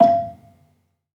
Gamelan
Gambang-E4-f.wav